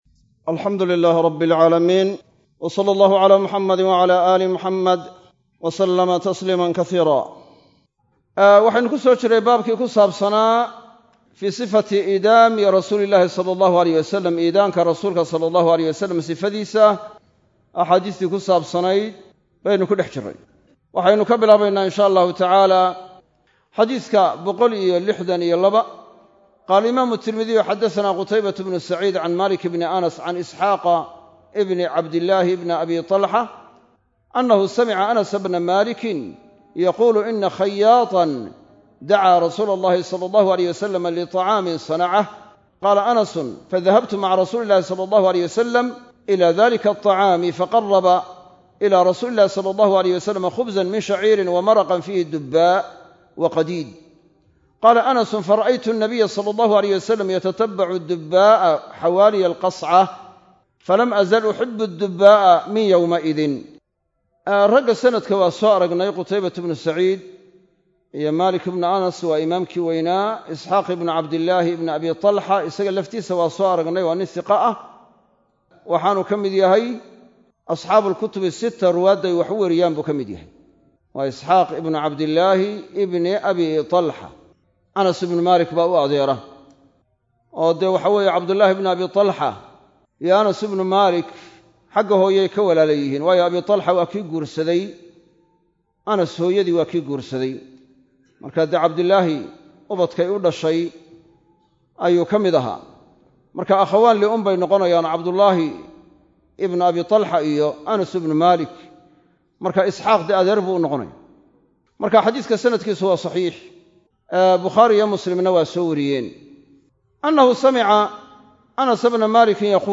Masjid Af-Gooye – Burco